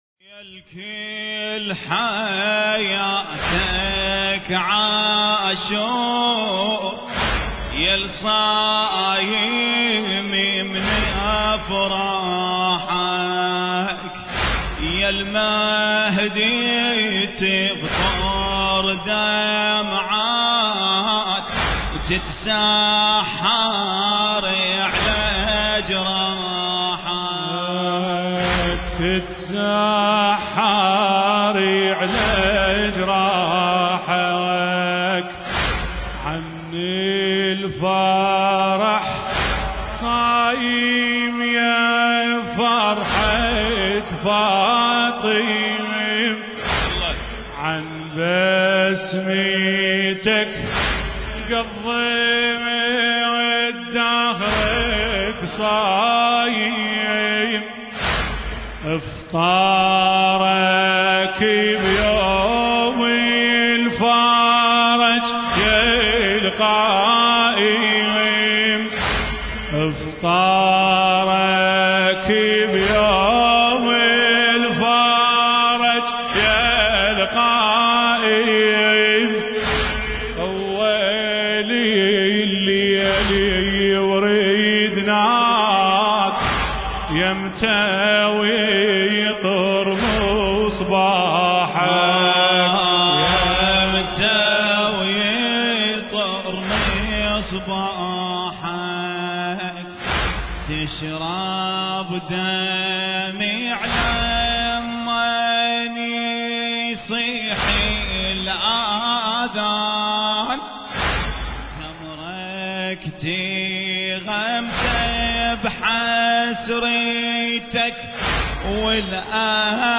المكان: حسينية أبي الفضل العباس (عليه السلام) – بغداد